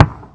ExhaustFire4.wav